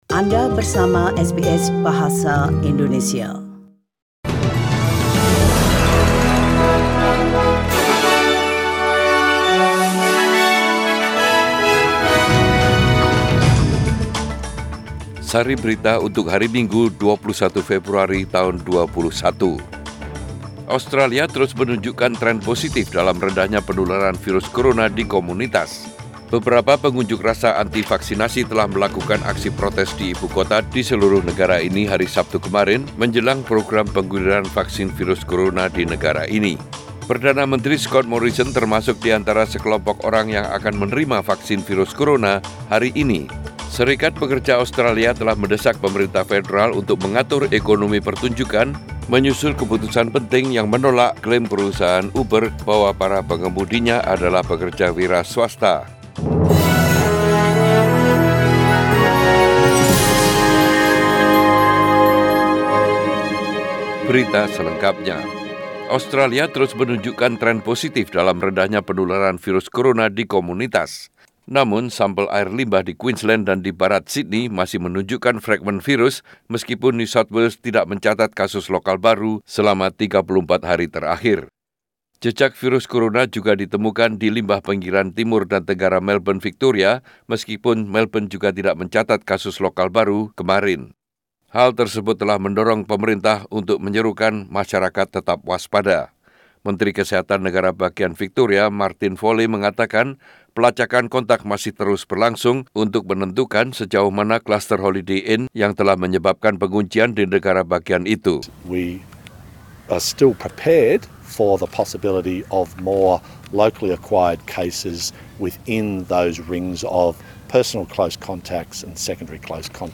SBS Radio News in Bahasa Indonesian - 21 February 2021